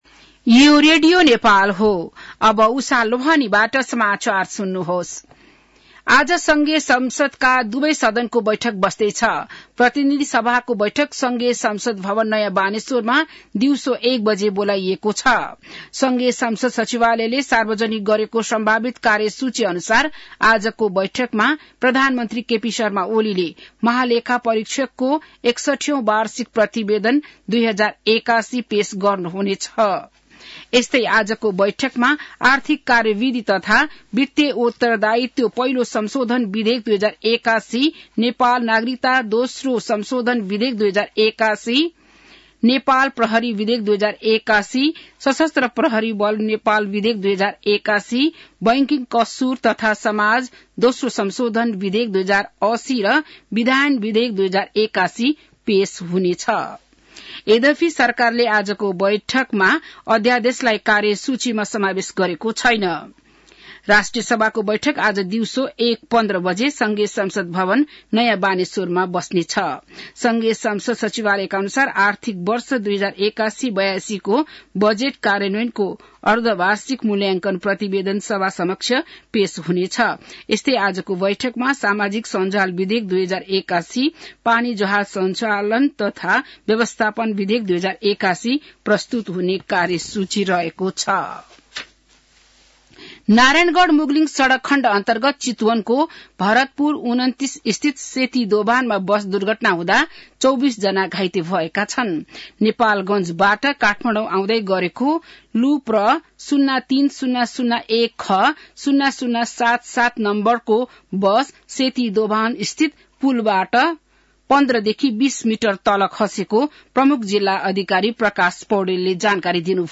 बिहान १० बजेको नेपाली समाचार : २८ माघ , २०८१